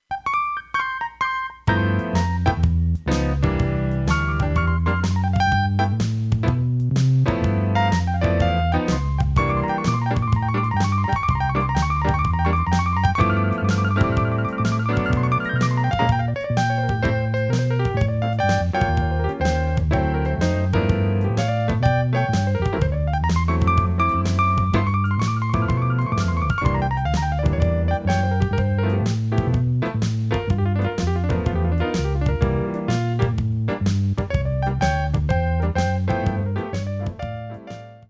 screaming piano and hammond solos
accompianment from a backing band